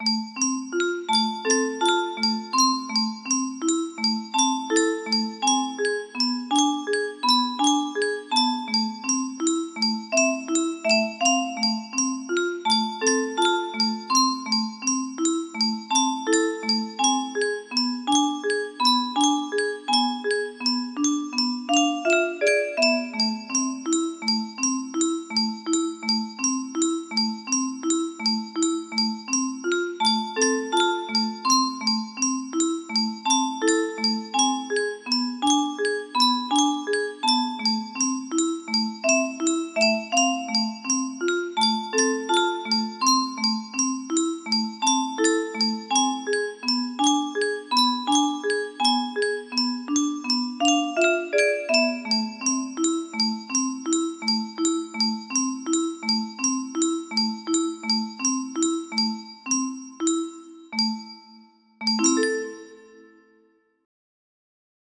BPM 60